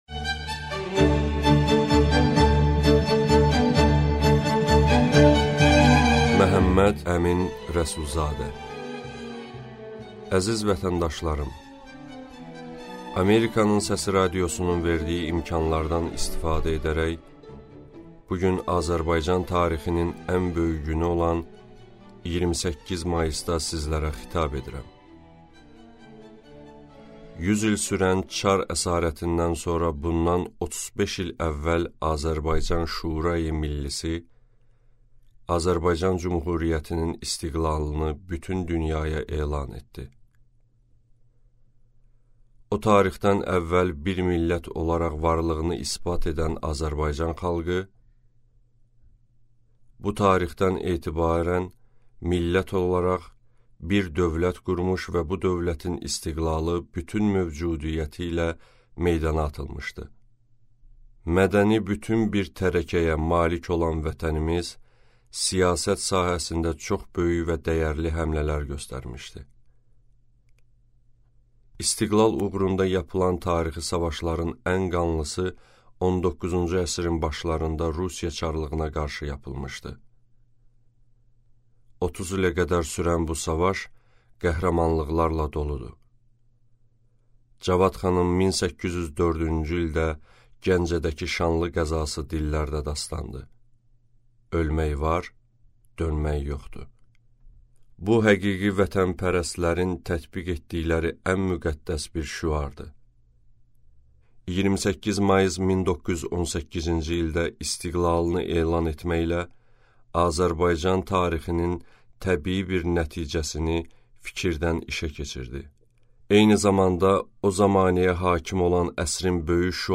Аудиокнига Əsrimizin siyavuşu | Библиотека аудиокниг